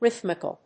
音節rhyth・mi・cal 発音記号・読み方
/ríðmɪk(ə)l(米国英語)/